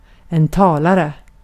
Ääntäminen
Synonyymit språkbrukare Ääntäminen Haettu sana löytyi näillä lähdekielillä: ruotsi Käännös Konteksti Ääninäyte Substantiivit 1. speaker GenAm US 2. docent amerikanenglanti Artikkeli: en .